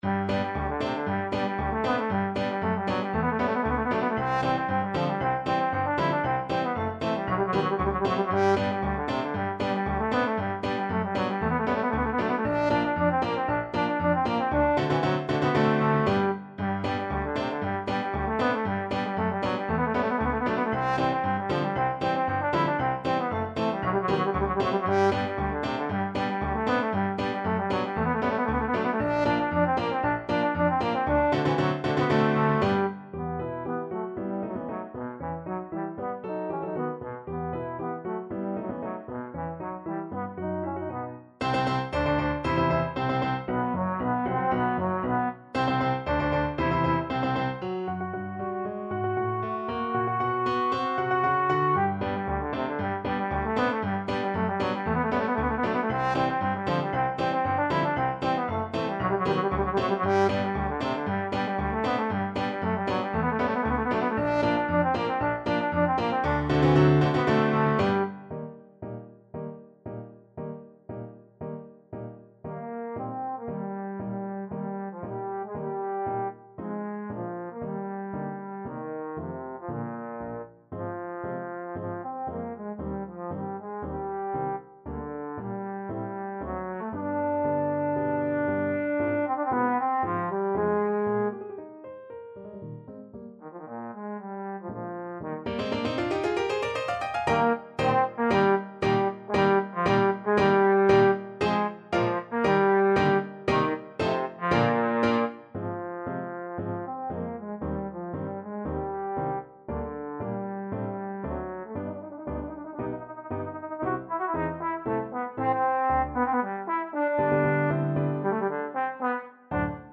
Trombone version
2/4 (View more 2/4 Music)
Classical (View more Classical Trombone Music)